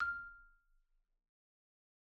Marimba_hit_Outrigger_F5_loud_01.wav